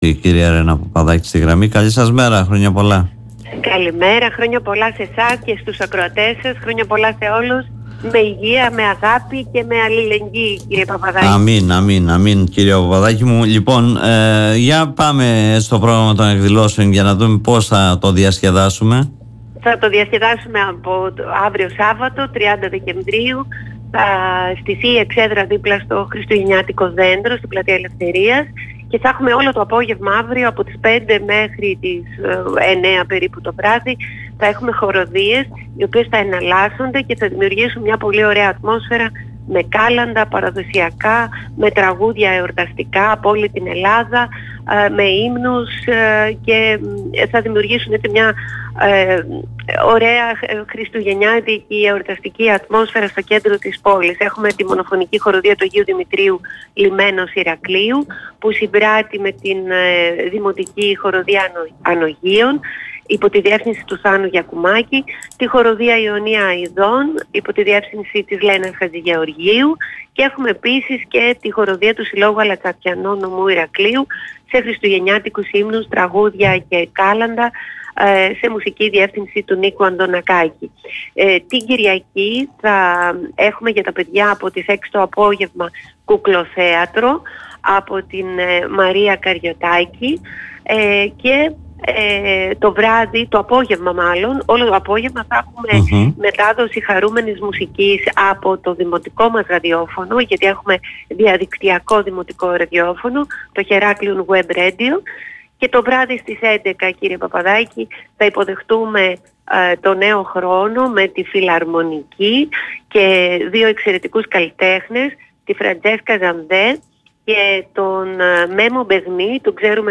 Με πλούσιο εορταστικό πρόγραμμα αποχαιρετούν το 2023 και υποδέχονται το 2024 ο Δήμος Ηρακλείου και η ΔΗΚΕΗ και σε αυτό αναφέρθηκε η αντιδήμαρχος Κοινωνικών Υπηρεσιών Ρένα Παπαδάκη μέσα από την εκπομπή “Δημοσίως” του politica 89.8.
Ακούστε την κ. Παπαδάκη: